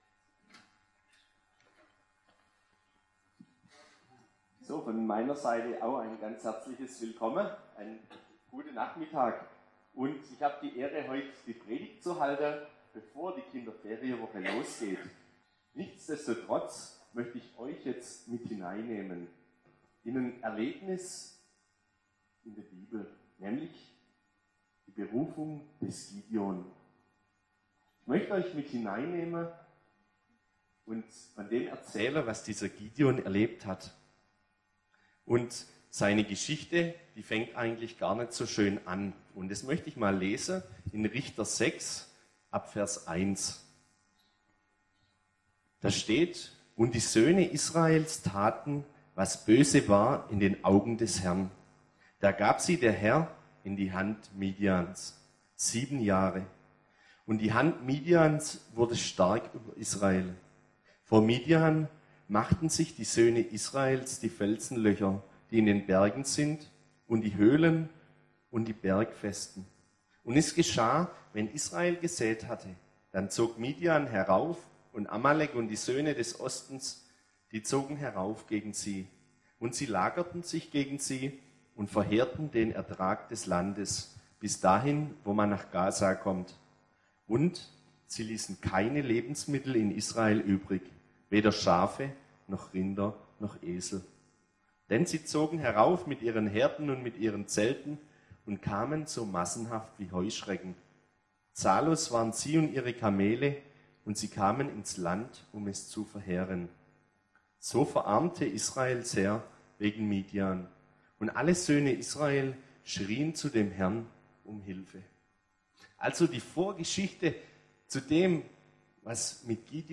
Eine Predigt bei der SV Dagersheim
September 2016 KiFeWo-Auftaktgottesdienst Teilen Audiomitschnitt Herunterladen